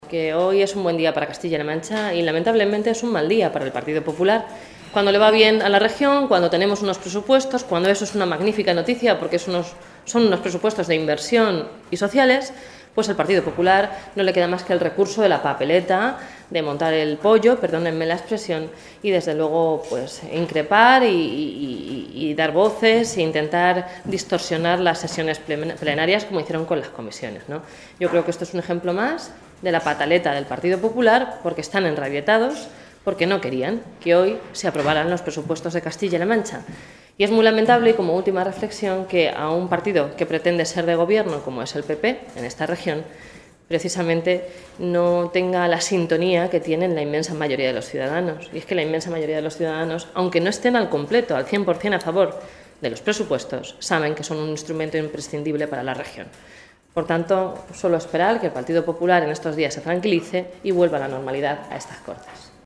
La portavoz del Grupo socialista en las Cortes de Castilla-La Mancha, Blanca Fernández, ha calificado como de muy triste que los parlamentarios del Grupo Popular “estén tan enrabietados” porque hoy se hayan aprobado los presupuestos regionales para 2017.
Cortes de audio de la rueda de prensa